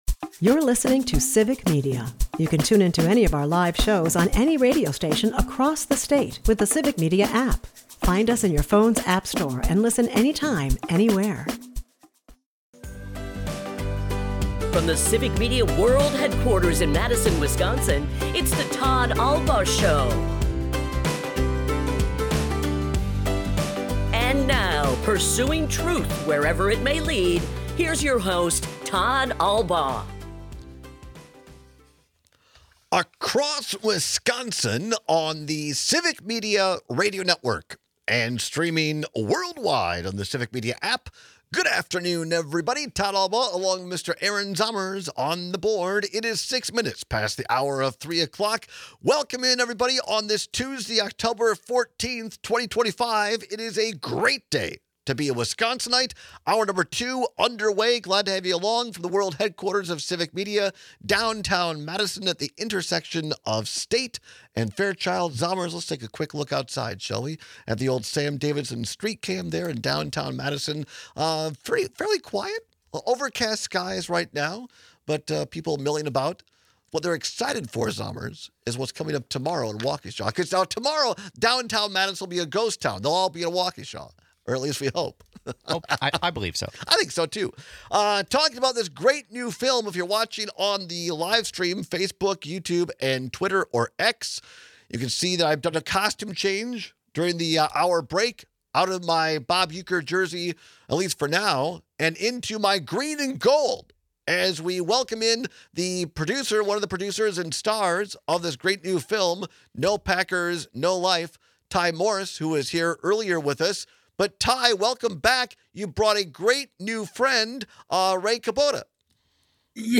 We take some calls and texts on how many carbs you can handle. We wrap up today’s show with a review of last night’s Brewers game and some excitement for tonight.